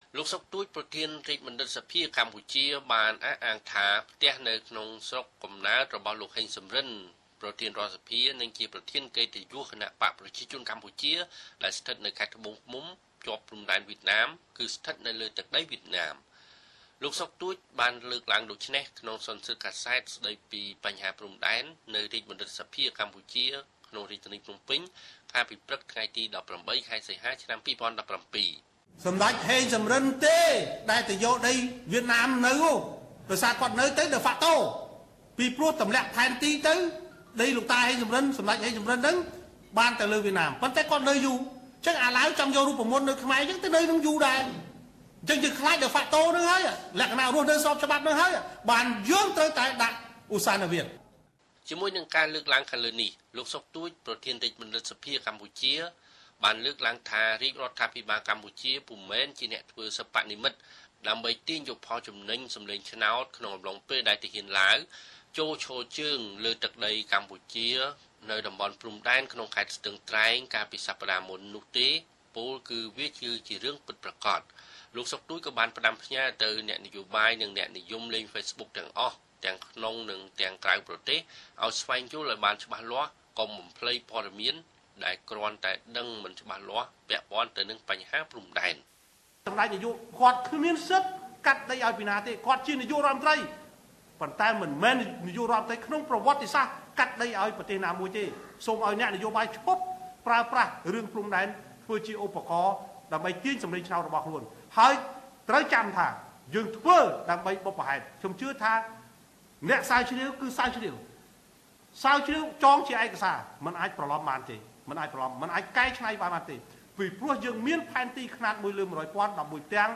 លោកសុខទូច បានលើកឡើងដូច្នេះ នៅក្នុងសន្និសីទកាសែត ស្តីពី បញ្ហាព្រំដែន នៅរាជបណ្ឌិត្យសភា ក្នុងរាជធានីភ្នំពេញ កាលពីព្រឹកថ្ងៃទី១៨ ខែសីហា ឆ្នាំ២០១៧។